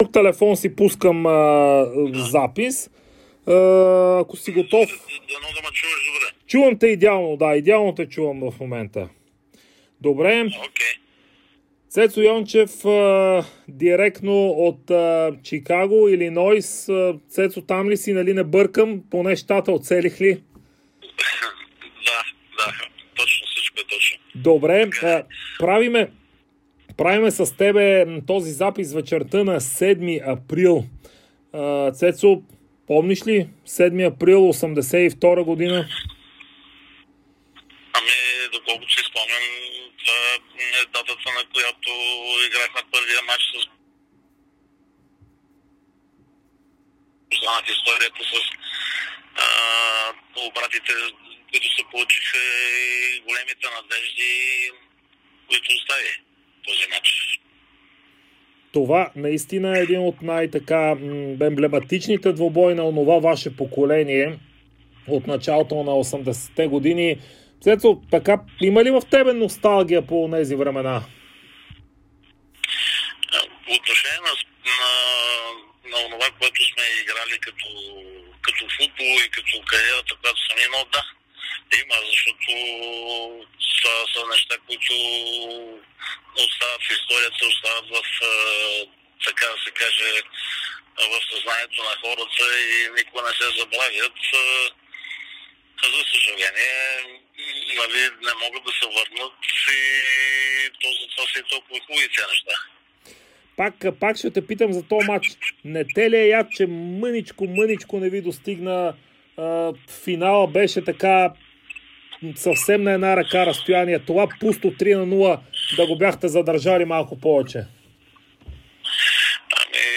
Легендата на ЦСКА Цветан Йончев даде специално интервю за Дарик и dsport, в което говори за амбициите на "червените" за титлата в efbet Лига, ситуацията с коронавируса в Щатите, както и знаменития мач на "армейците" срещу Байерн Мюнхен на 7 април 1982 година.